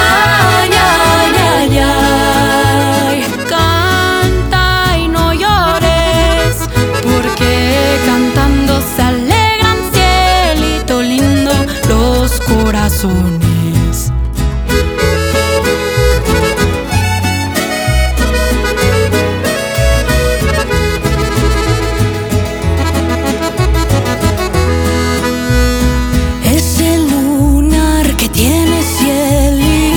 Latin Música Mexicana